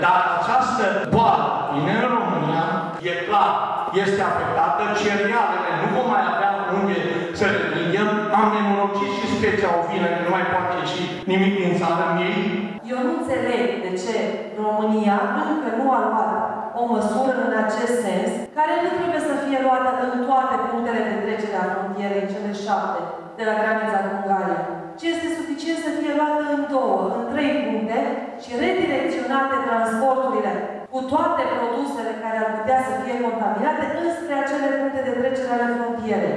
Fermierii prezenți la discuții sunt îngrijorați de apariția febrei aftoase, care le-ar distruge afacerile, și au criticat lipsa unor măsuri de prevenție.